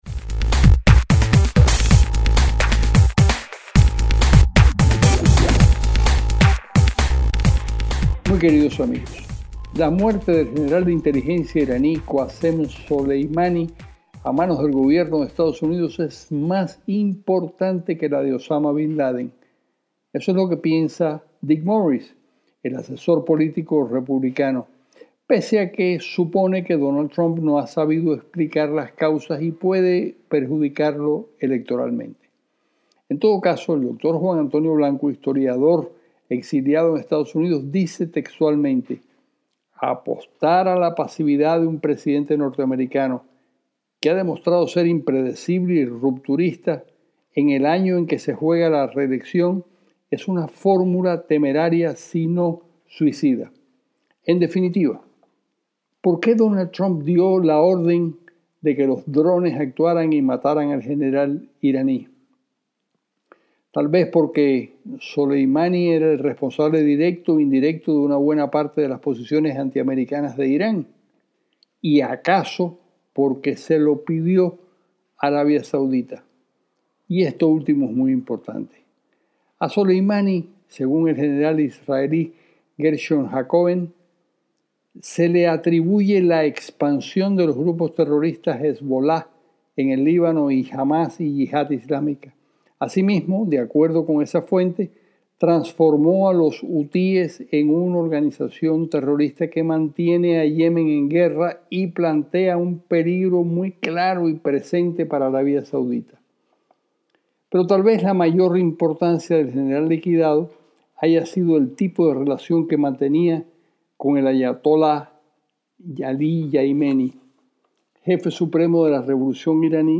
LA OPINIÓN DE CARLOS ALBERTO MONTANER - En exclusiva para Radio Sefarad, el periodista cubano – español afincado en EE.UU. Carlos Alberto Montaner, nos habla de las razones del ataque de Trump contra el general iraní, encendiendo las alertas de la zona y mundiales.